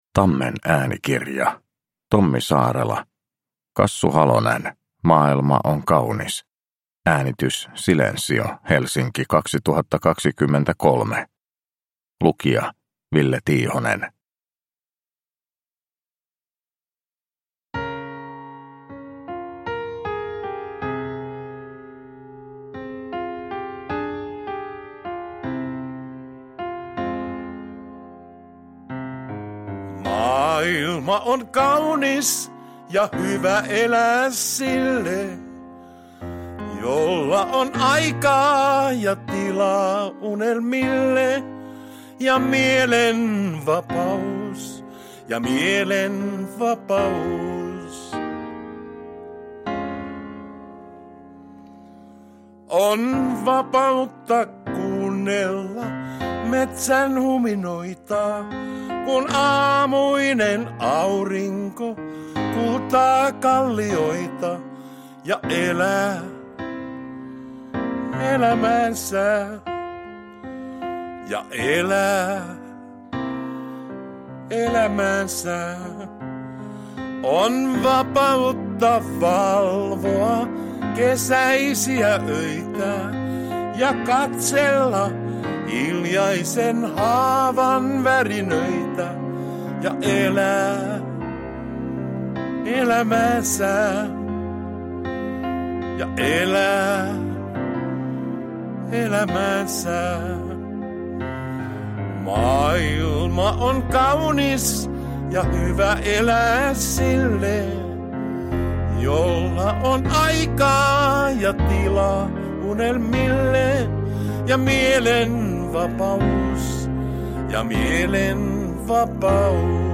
Kassu Halonen – Ljudbok – Laddas ner